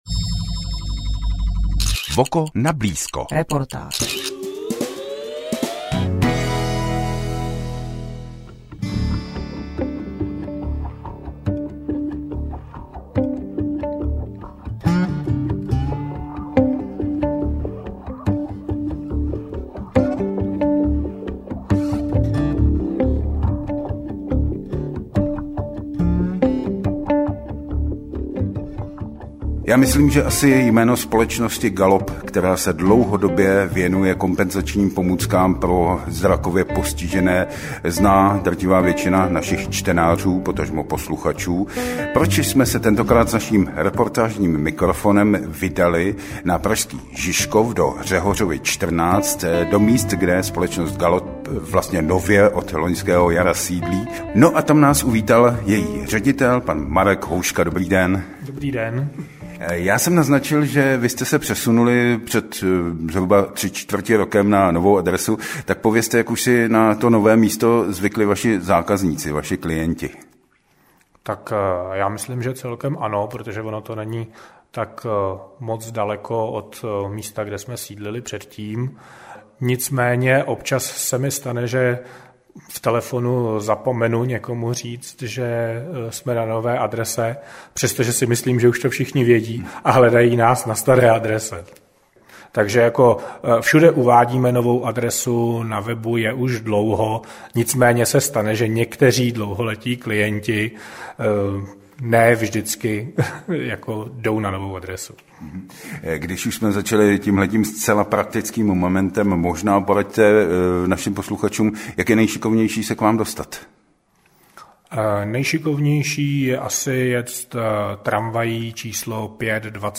V únorovém čísle audiožurnálu VOKO si v rubrice Nablízko můžete poslechnout reportáž z GALOPu (formát MP3, 11 minut, 10 MB) .